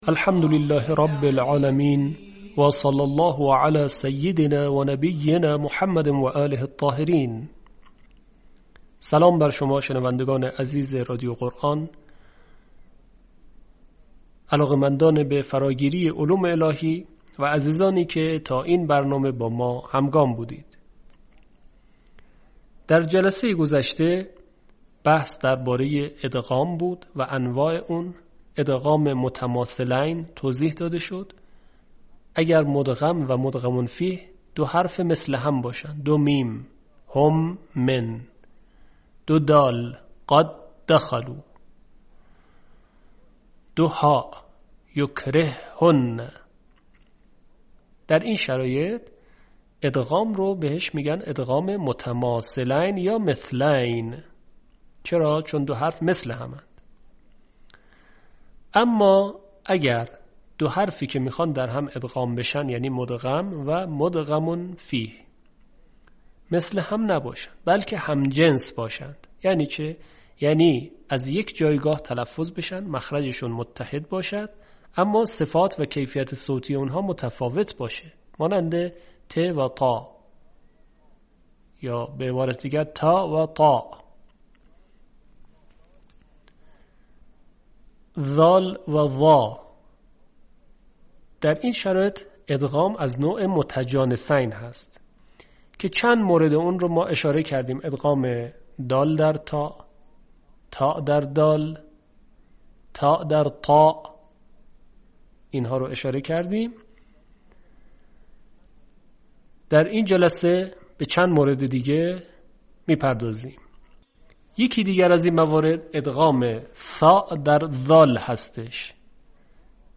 یکی از مهم‌ترین سیاست‌های ایکنا نشر مبانی آموزشی و ارتقای سطح دانش قرائت قرآن مخاطبان گرامی است. به همین منظور مجموعه آموزشی شنیداری (صوتی) قرآنی را گردآوری و برای علاقه‌مندان بازنشر می‌کند.